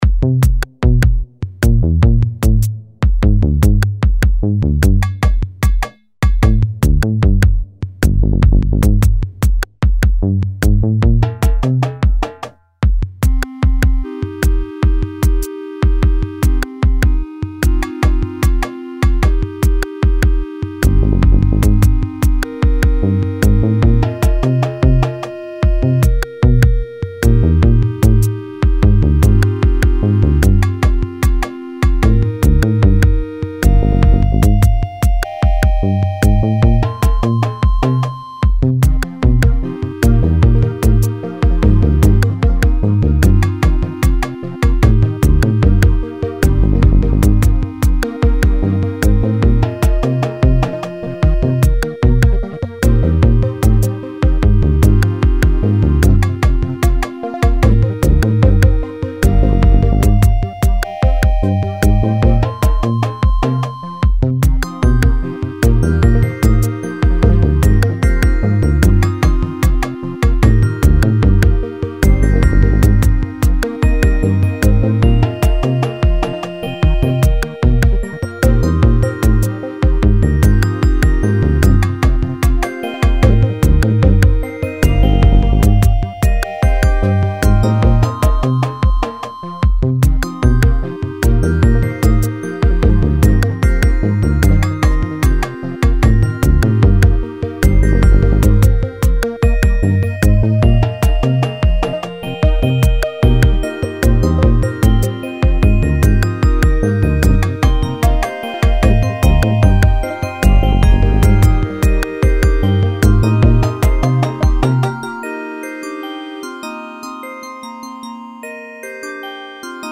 light-hearted, rhythmic